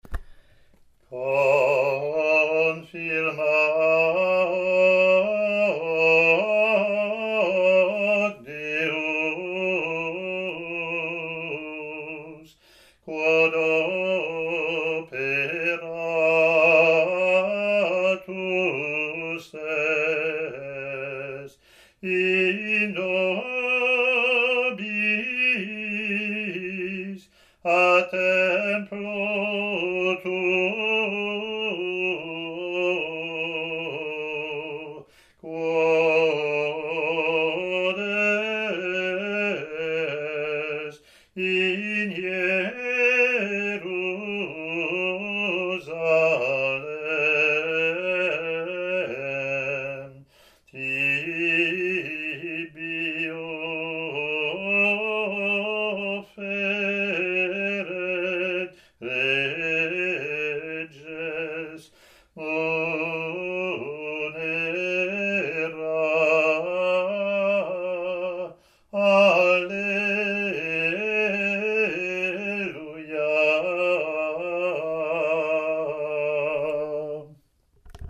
Latin antiphon)